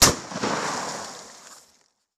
8 bits Elements
Weapons Demo
scatter_gun_2.wav